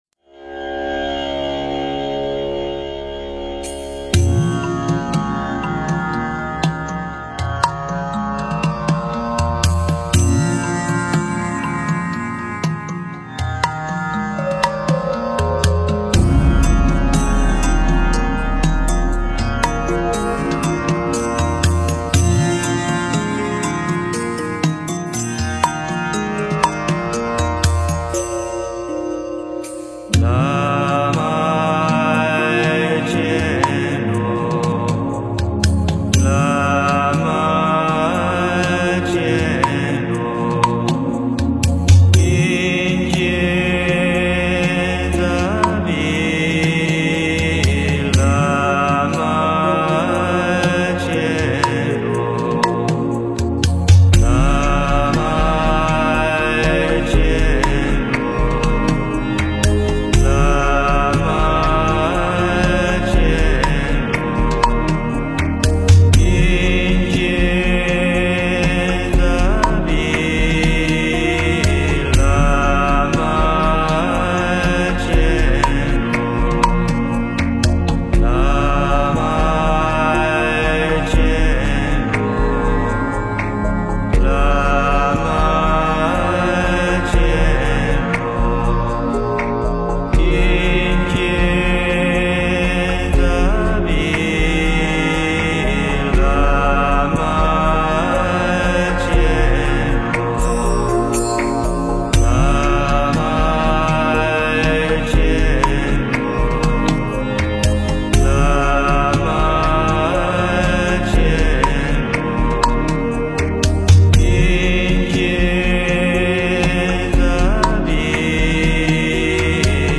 诵经
佛音 诵经 佛教音乐 返回列表 上一篇： 大悲咒(梵音